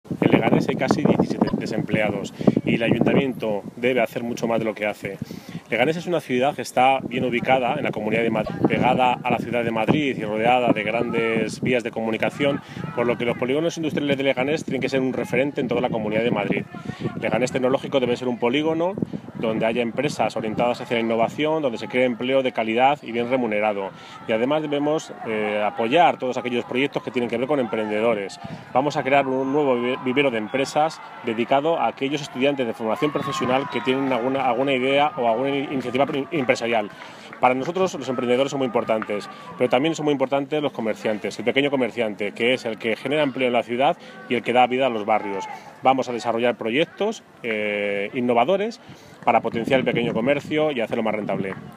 Audio en el que Santiago Llorente, PSOE Leganés, presenta sus propuestas de empleo.